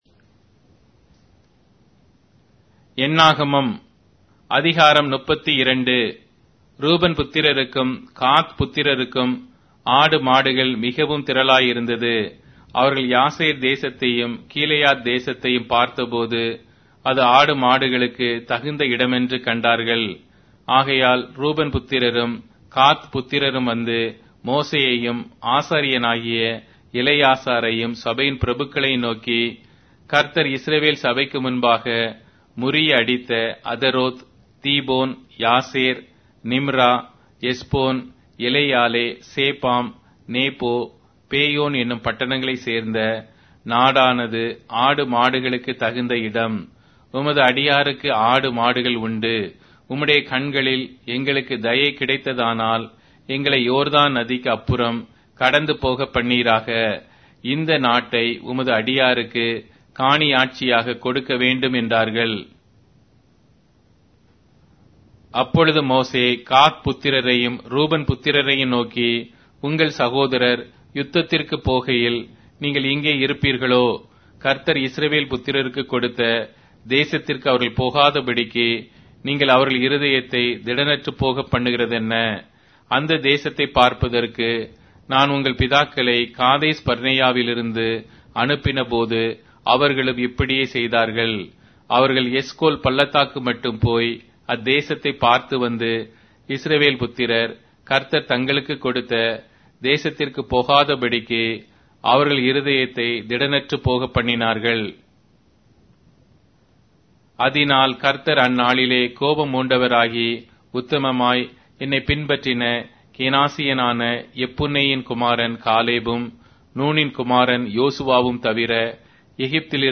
Tamil Audio Bible - Numbers 30 in Alep bible version